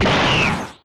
girl_charge_0.wav